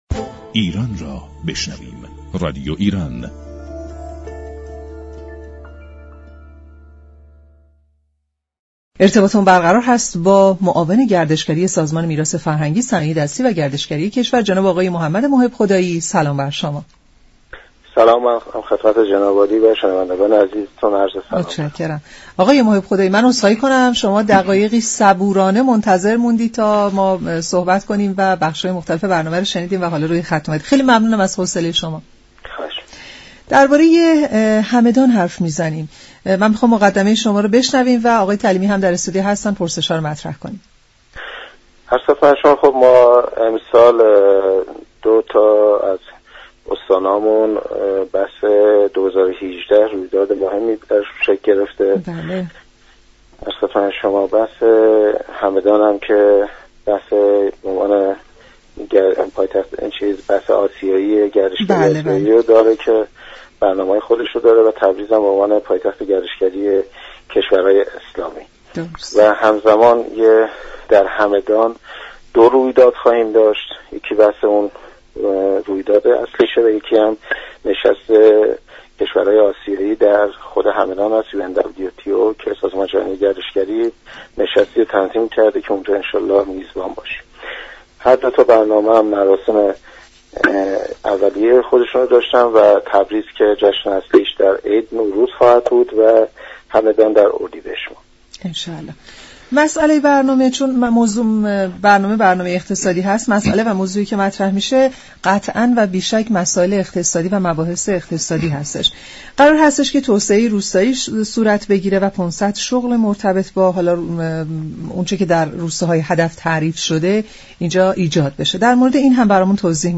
معاون گردشگری سازمان میراث فرهنگی و صنایع دستی در گفت و گو با نمودار گفت: كشورمان شرایط جذب 2 میلیون توریست را دارد به شرط آنكه بتواند با برنامه های صحیح از ظرفیت های موجود استفاده كند.